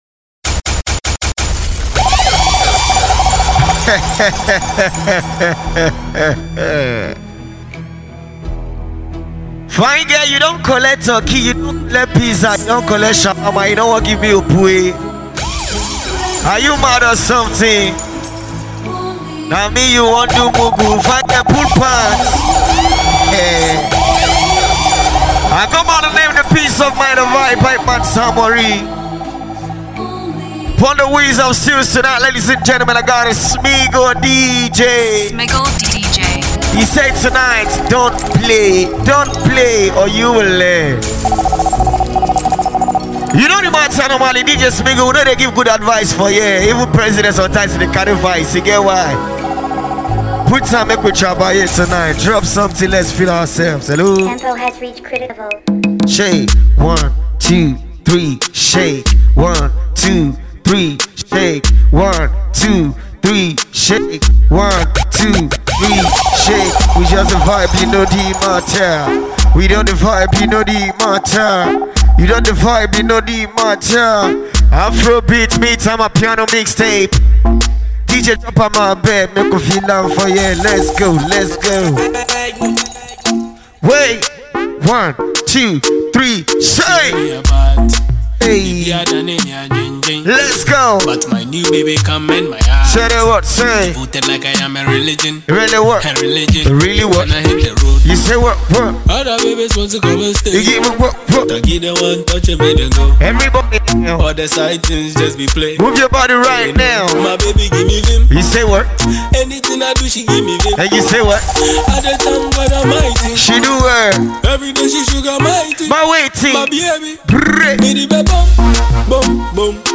DJ Mixtape